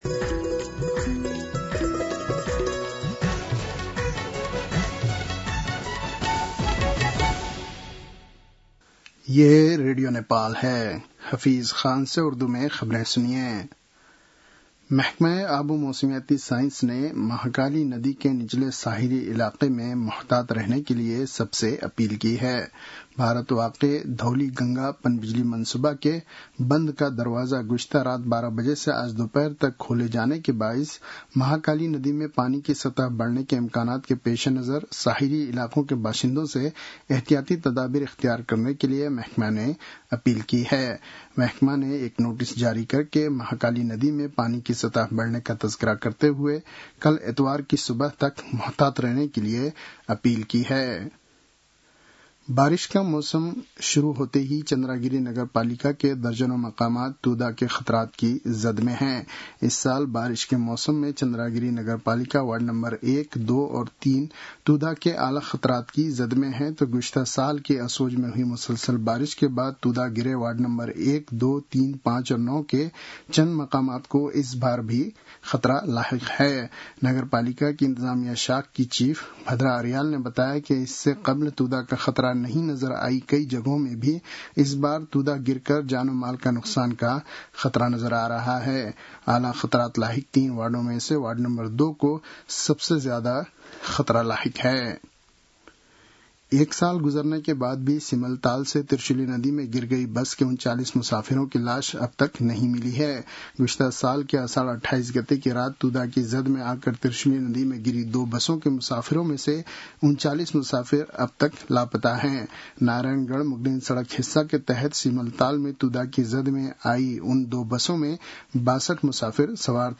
उर्दु भाषामा समाचार : २८ असार , २०८२